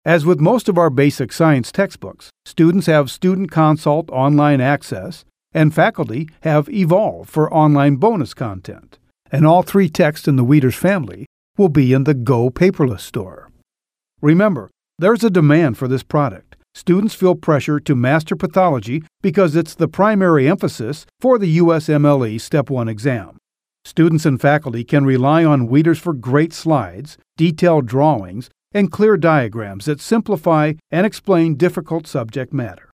Male Narrators
These are sample narrations in the eLearning style.